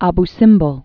b sĭmbəl, -bĕl)